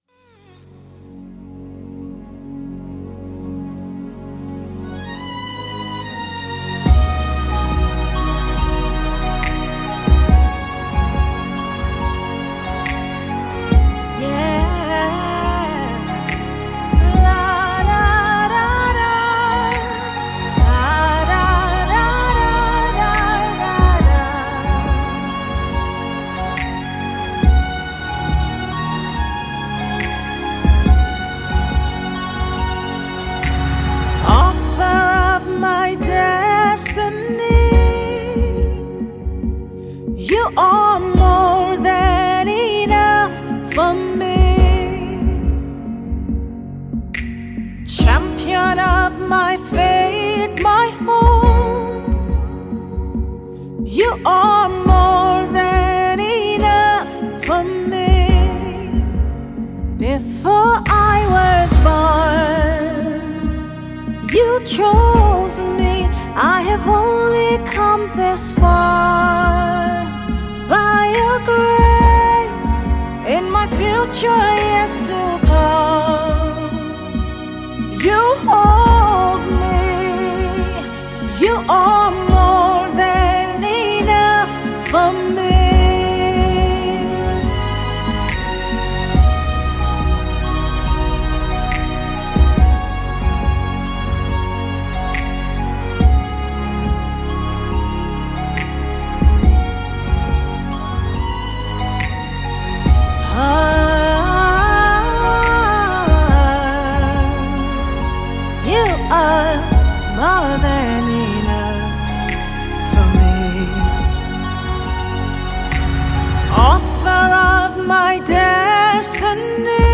Mp3 Gospel Songs
a Female Nigerian gospel songstress
It was her dazzling studio album